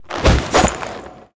rogue_skill_sneak_attack_end.wav